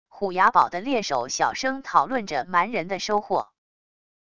虎崖堡的猎手小声讨论着蛮人的收获wav音频